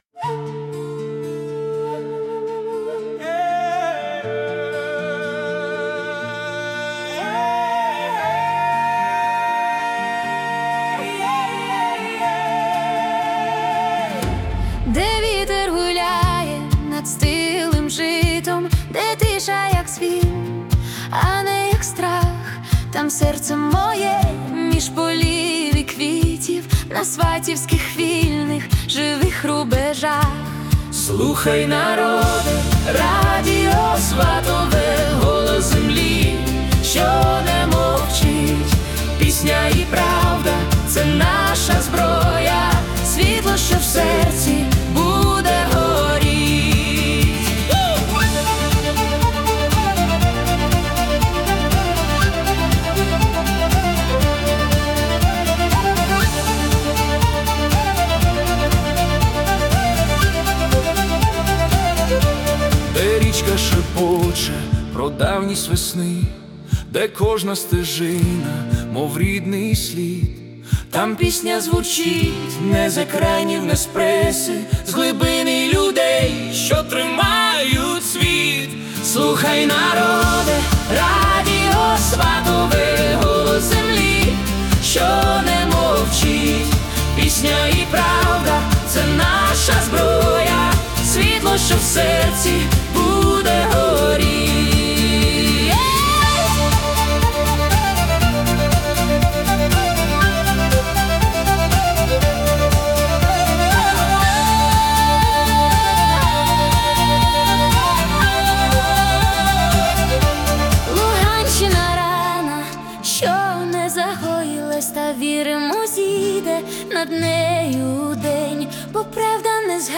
Ukrainian Folk-Pop Anthem
це енергійний та надихаючий гімн